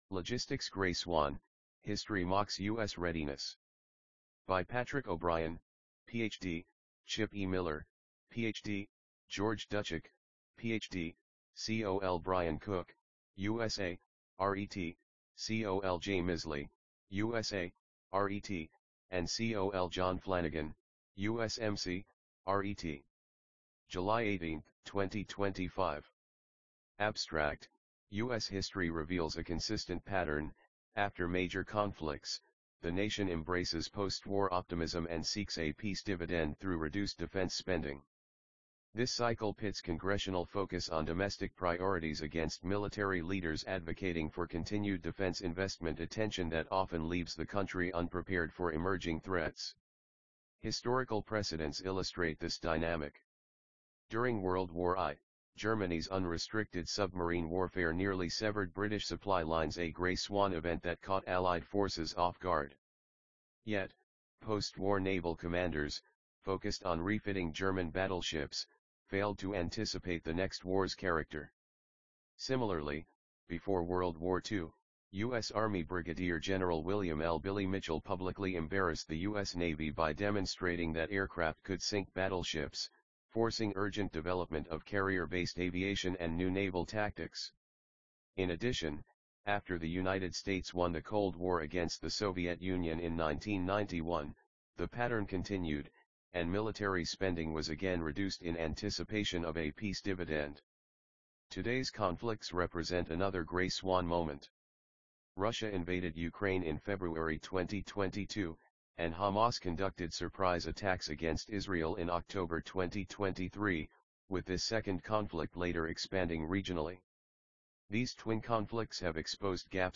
EXP_Logistics Gray Swan_AUDIOBOOK.mp3